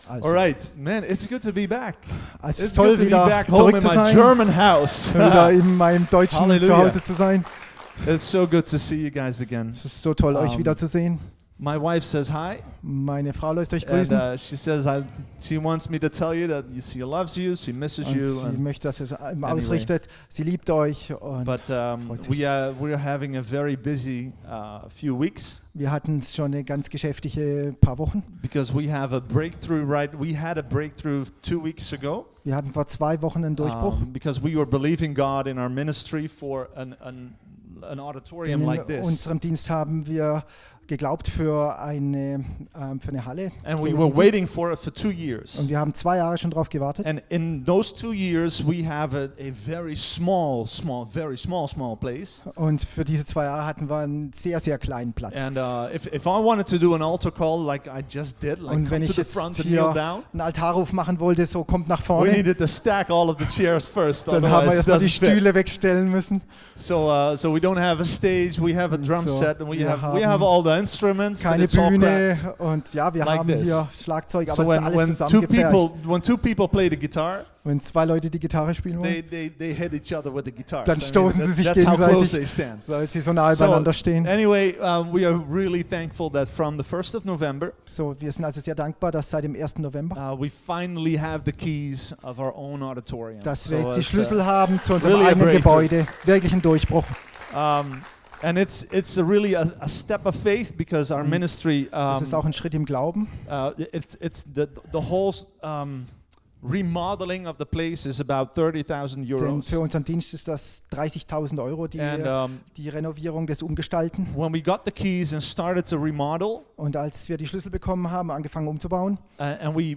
Lobpreisabend | Oase Christengemeinde